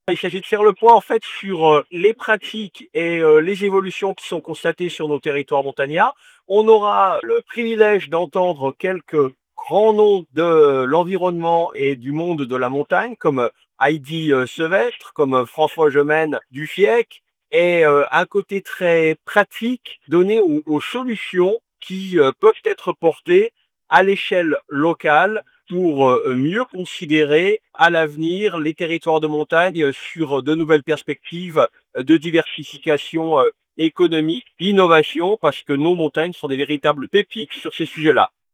Eric Fournier est le maire de Chamonix.